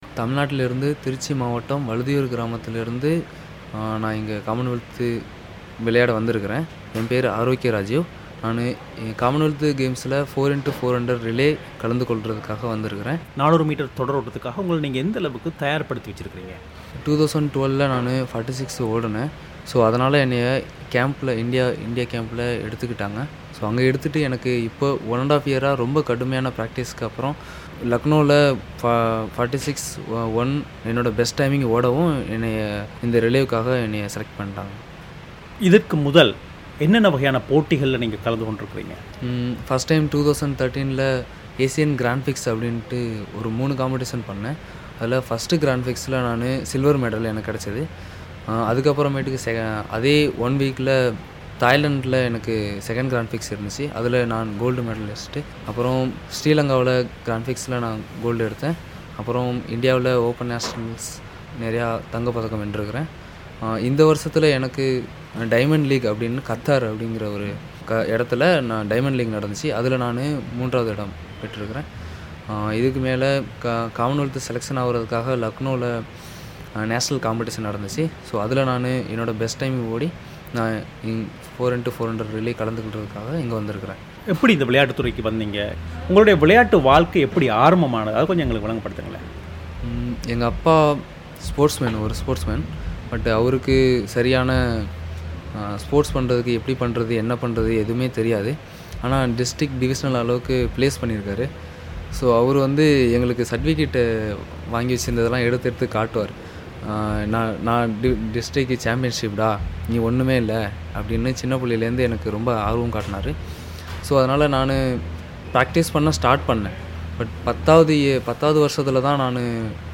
நேர்காணல்